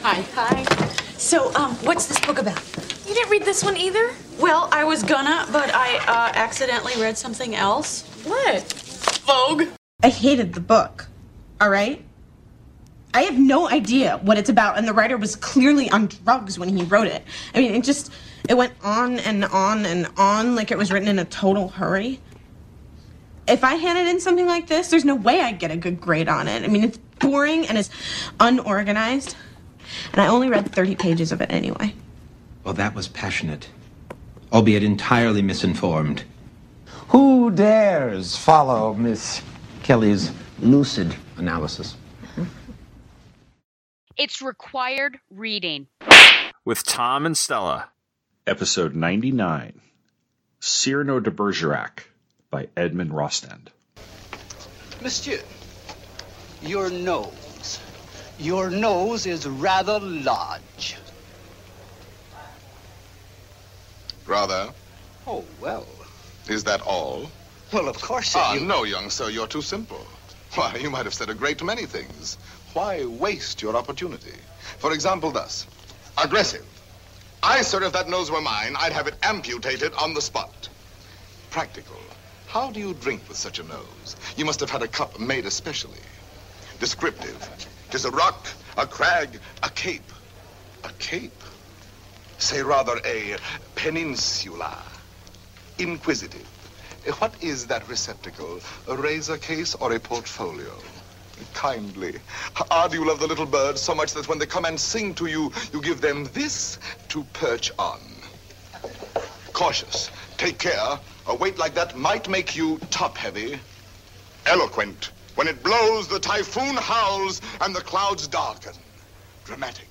is two teachers talking about literature. Each episode, we will be taking a look at a single work, analyzing it, criticizing it and deciding if it’s required reading.